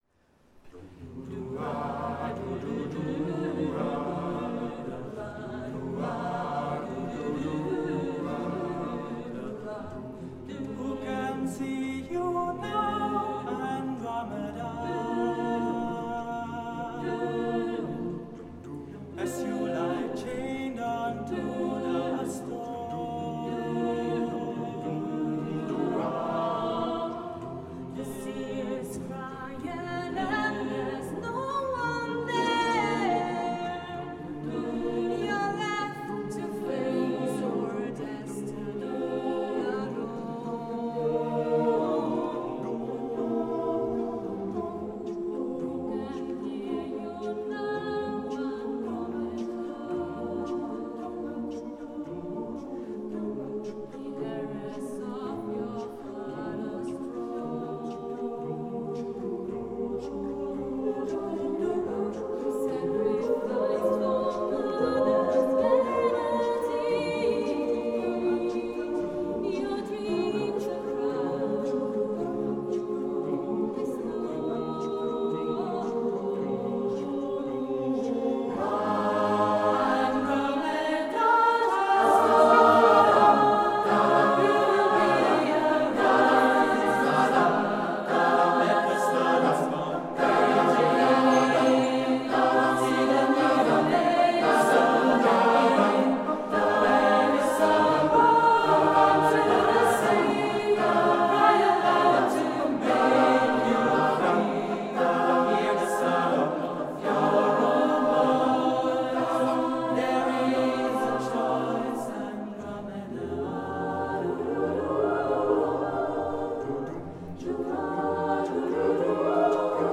CD 6 - Live in Concert 2021
Tëuta su ai 12.09.2021 tla dlieja de Urtijëi